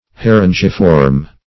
Search Result for " harengiform" : The Collaborative International Dictionary of English v.0.48: Harengiform \Ha*ren"gi*form\ (h[.a]*r[e^]n"j[i^]*f[^o]rm), a. [F. hareng herring (LL. harengus) + -form.] Herring-shaped.